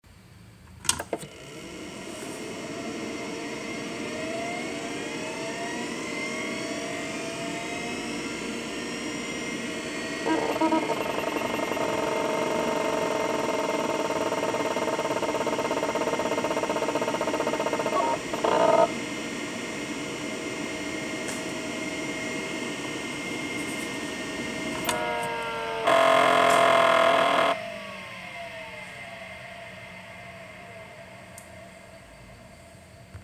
Seagate hard disk boot-up
The ST-251 was a 42 MB hard disk drive, made by Seagate in the early 1980s. The recording has the drive spinning up and self-testing (as it would do when turned on) and then spinning down (being turned off).